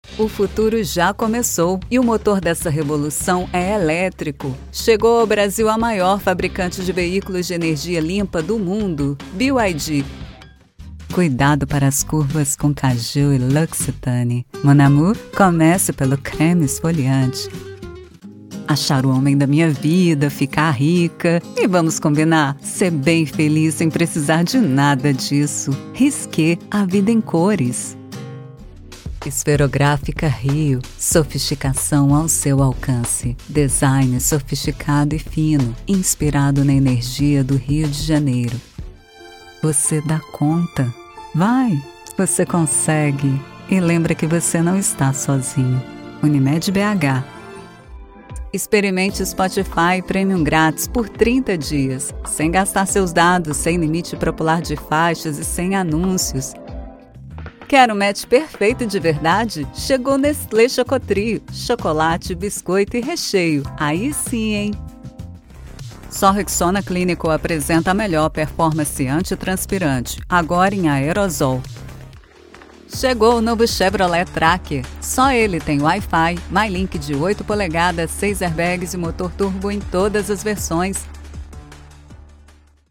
Brazil
bright, captivating, commercial, conversational, elegant, empathic, friendly, informative, modern, seductive, smooth
Compilation Demo